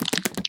mob / spider / step1.ogg
step1.ogg